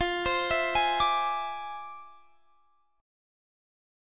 FmM13 Chord
Listen to FmM13 strummed